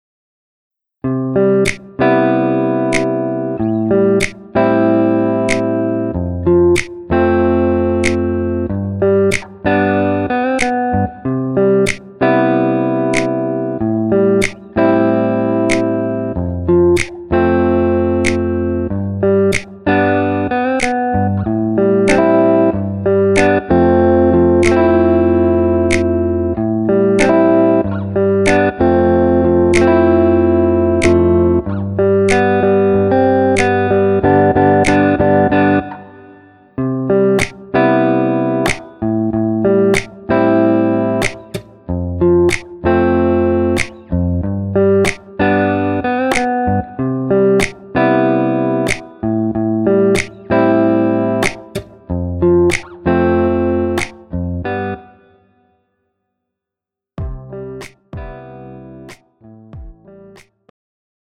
음정 원키
장르 pop 구분 Pro MR